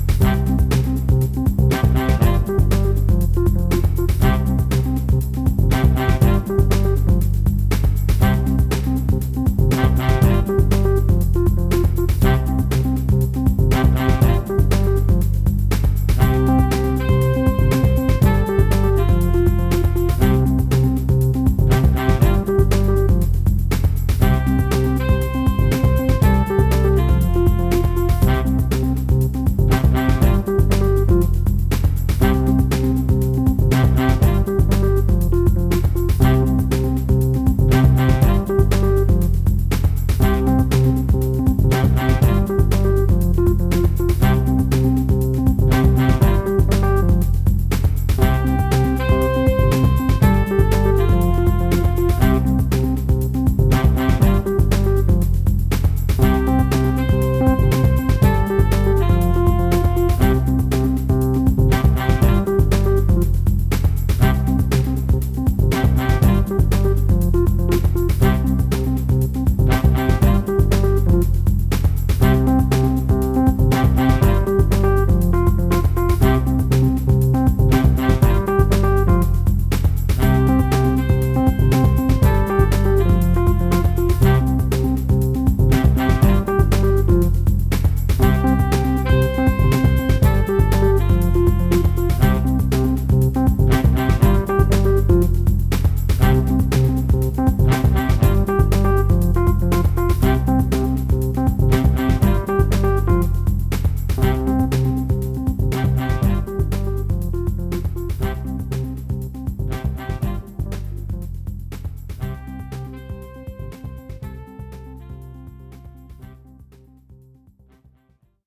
Easy Listening / Datum: 13.05.2023